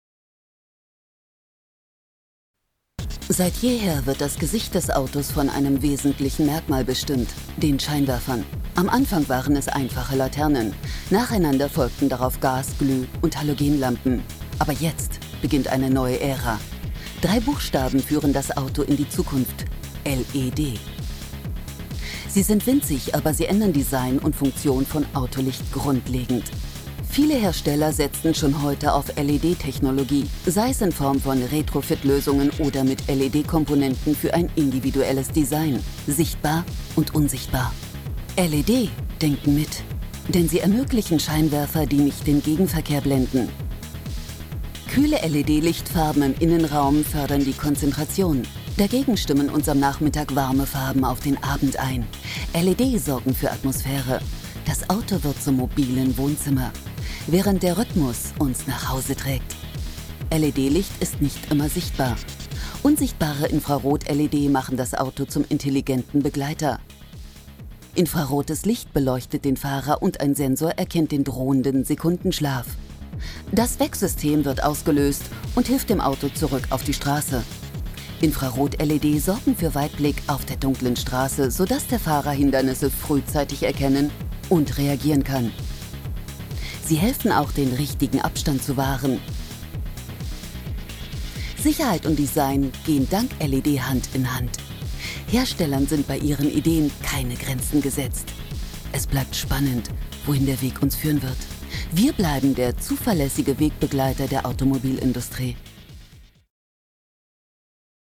variable Stimme von Mittellage über Comic bis tief lasziv
Sprechprobe: Industrie (Muttersprache):